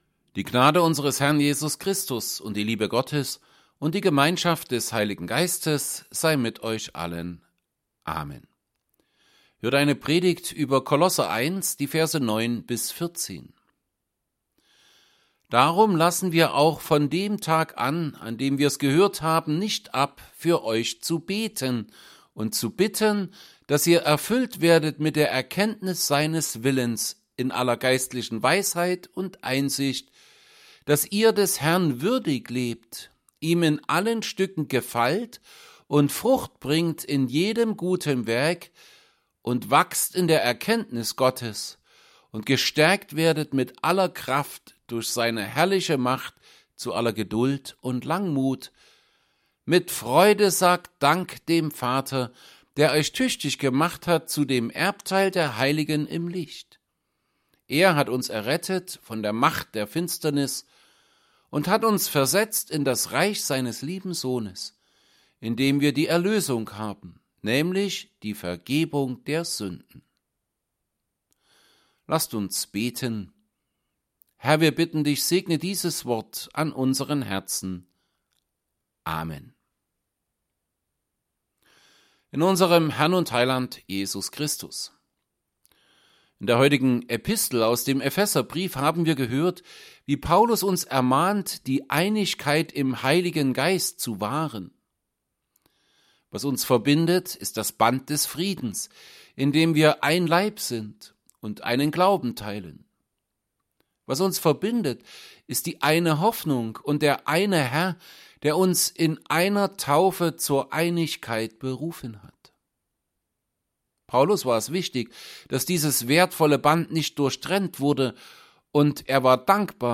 Predigt_zu_Kolosser_1_9b14.mp3